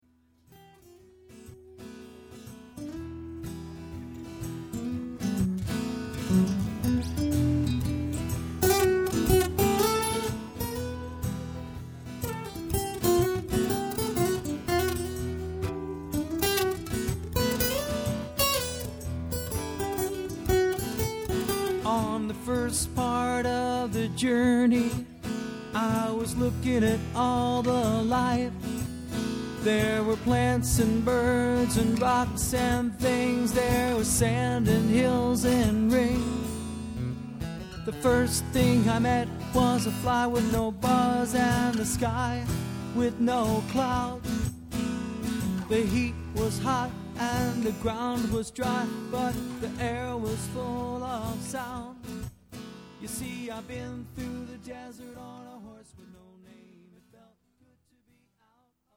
Live Song Samples
Live Demos